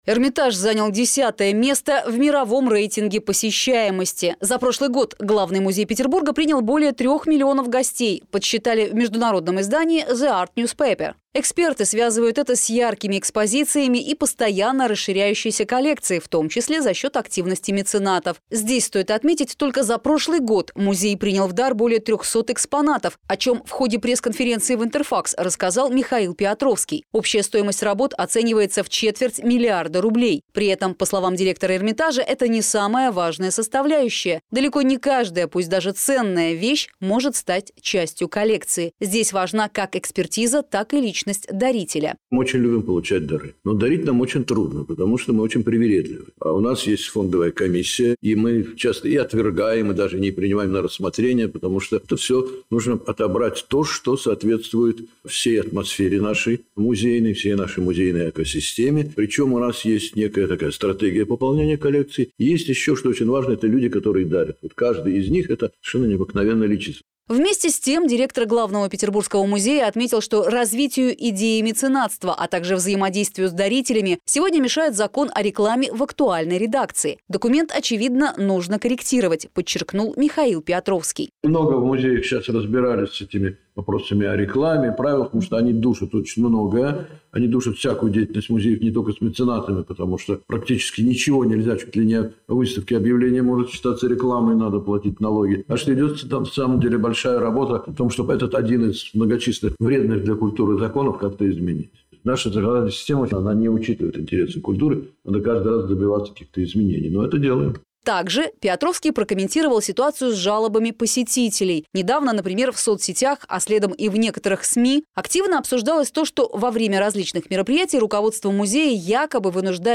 Здесь стоит заметить, только за прошлый год музей принял в дар более 300 экспонатов, о чем в ходе пресс-конференции в «Интерфаксе» рассказал Михаил Пиотровский.